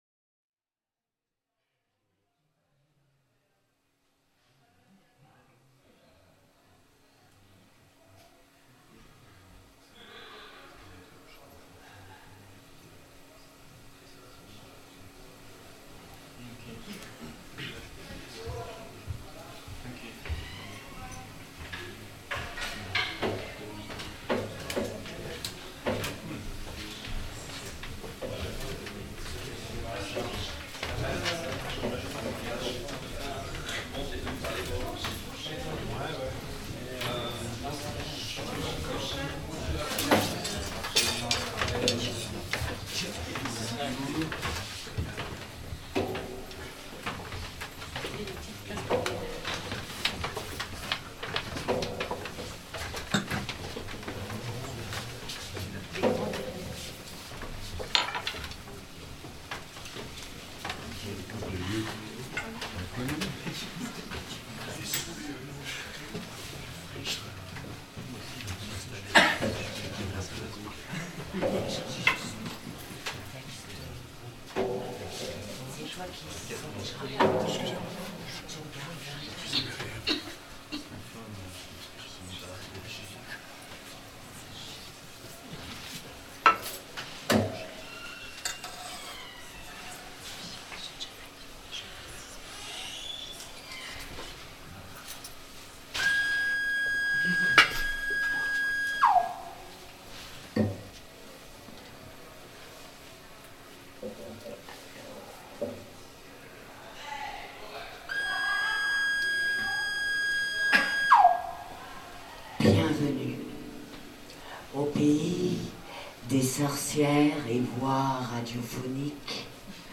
boui-boui à ressorts Le Cabinet / Genève
performances
enregistrement live